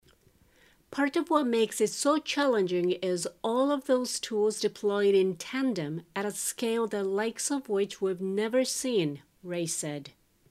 ナチュラルスピード：